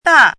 chinese-voice - 汉字语音库
da4.mp3